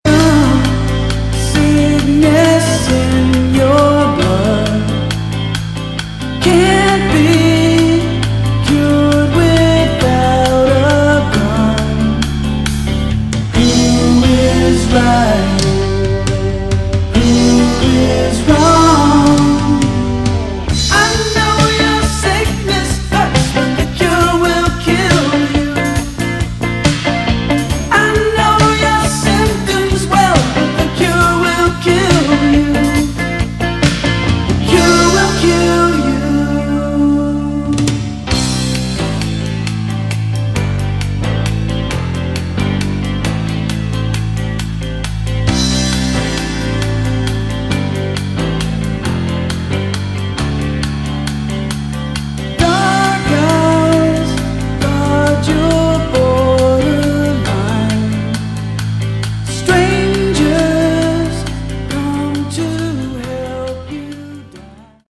Category: AOR
Original Demo